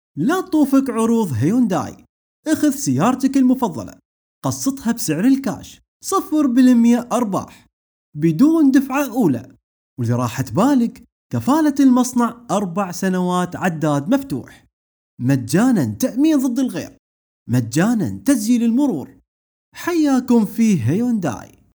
تم تسجيل هذا الاعلان لصالح وكيل سيارات هيونداي في الكويت وهو عبارة عن تعليق صوتي لشرح عروض الشركة في فبراير في فترة الاعياد الوطنية
اعلان تجاري سيارات هيونداي الكويت